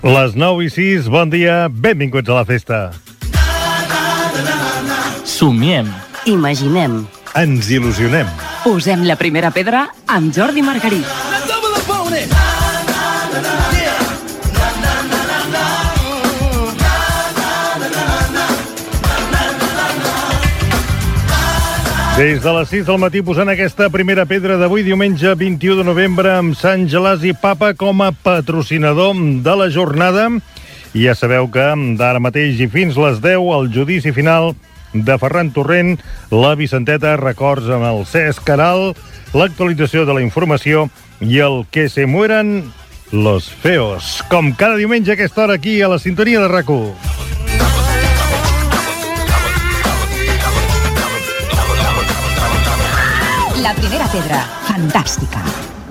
Careta del programa, sumari de 09.00 a 10.00 i indicatiu del programa.
Entreteniment
FM